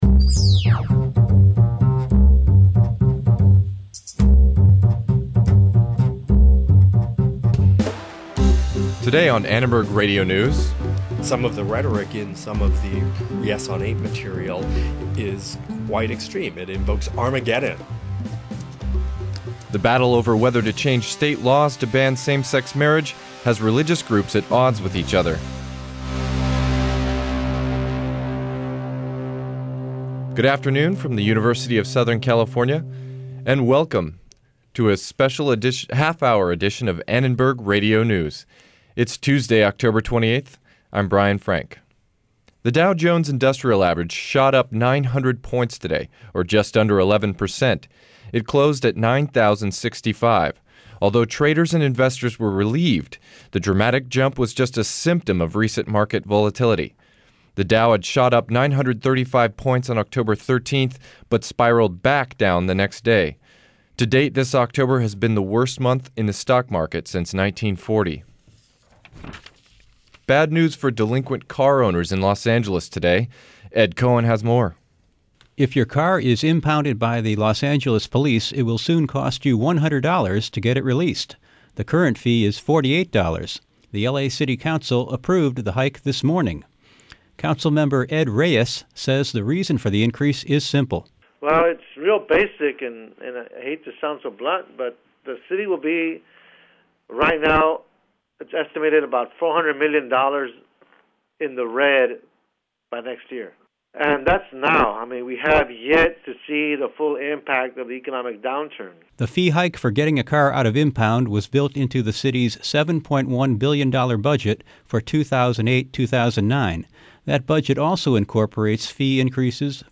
We ask voters like what they know--or don't know--about superior court judges.